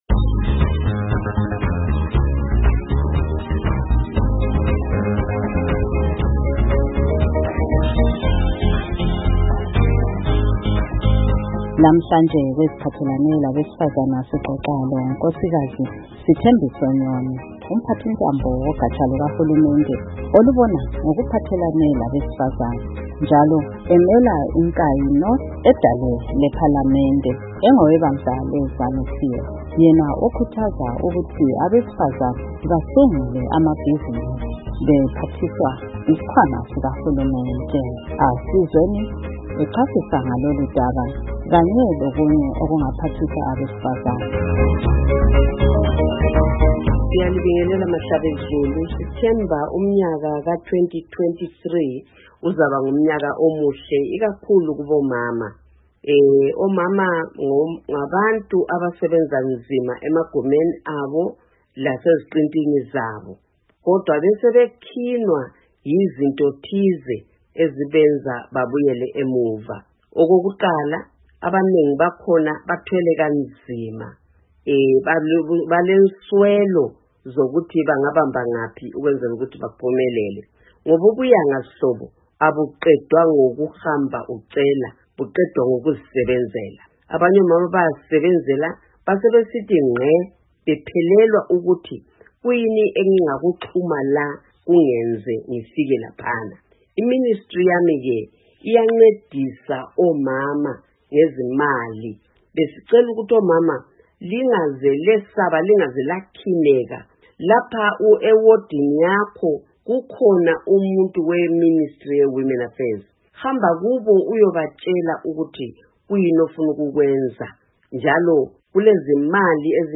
Asizweni umphathintambo wogatsha olubona ngeziphathelane labesifazana uNkosikazi Sithembiso Nyoni echasisa kabanzi ngaloludaba kungxoxo ayiqhube leStudio 7.
Ingxoxo loNkosikazi Sithembiso Nyoni